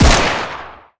Decay/sound/weapons/arccw_ud/uzi/fire-05.ogg at main